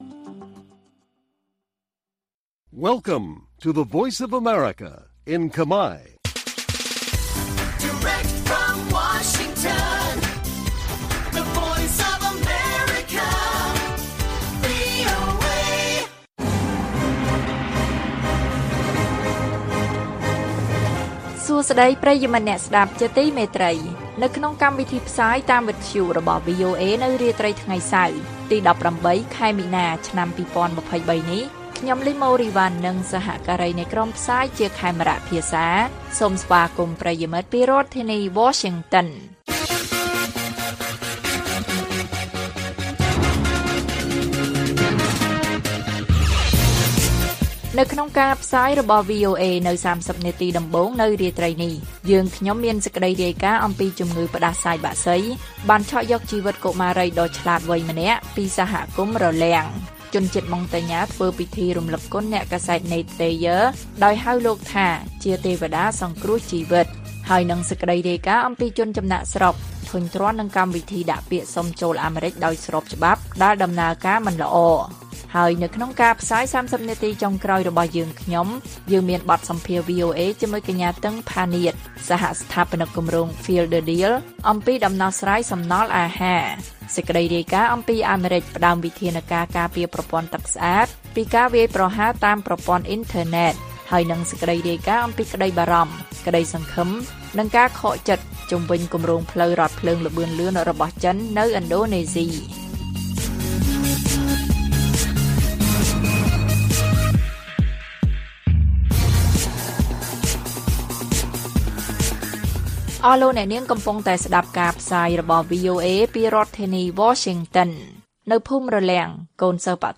ព័ត៌មានពេលរាត្រី ១៨ មីនា៖ បទសម្ភាសន៍ VOA៖ ដំណោះស្រាយសំណល់អាហារ